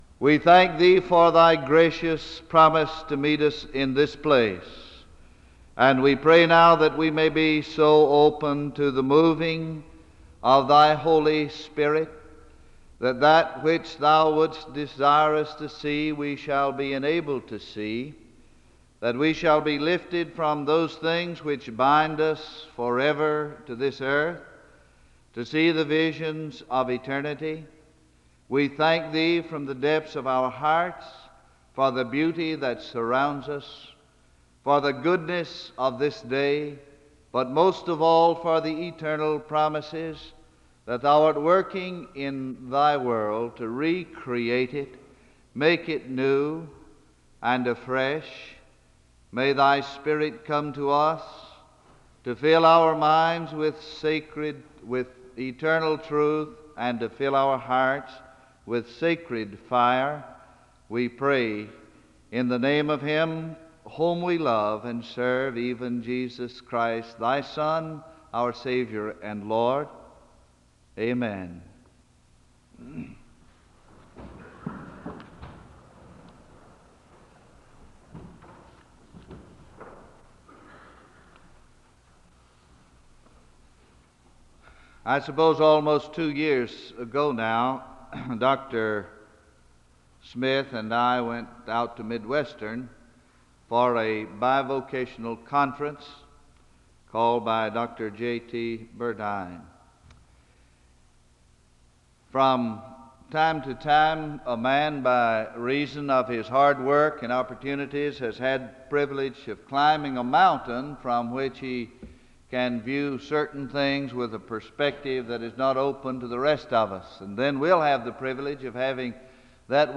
The service begins with a word of prayer (00:00-01:13).
The choir leads in a song of worship (05:02-09:12).